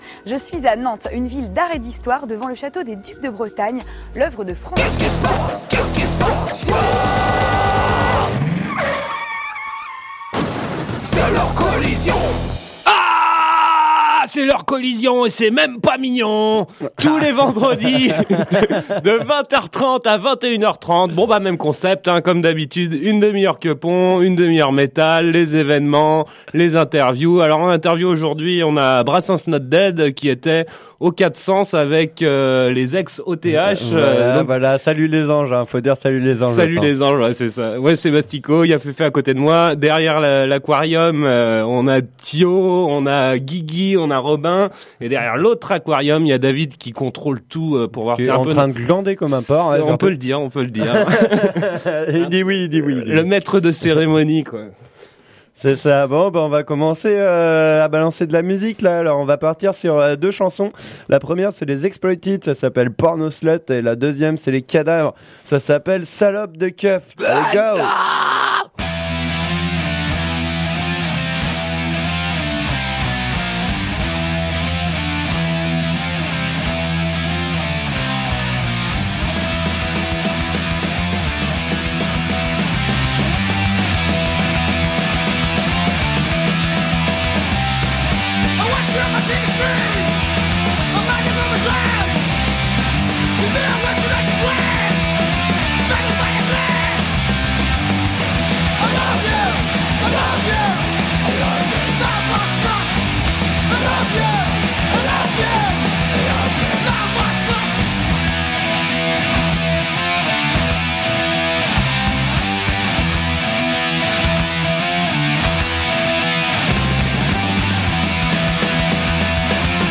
L'Heure Kolision du 29/11/2013 avec l'interview de Brassen's Not Dead réalisée lors de leur passage au 4 Sens
Partie Punk
Partie Métal